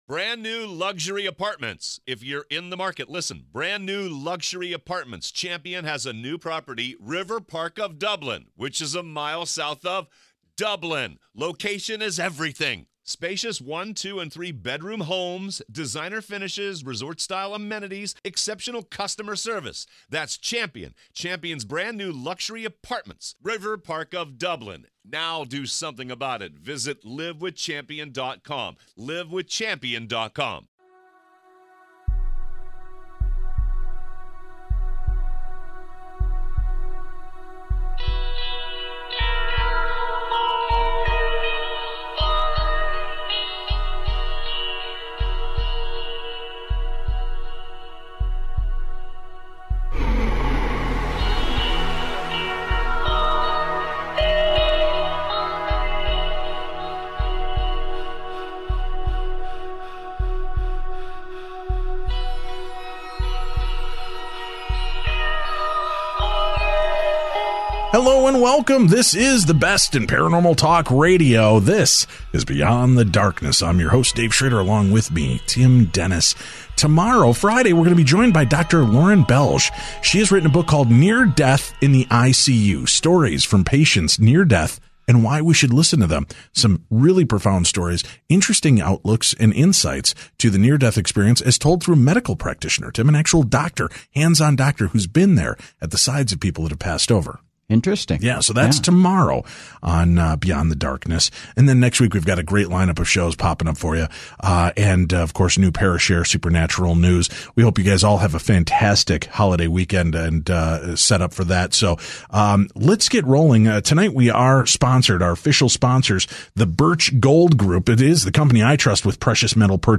This is the best in paranormal talk radio.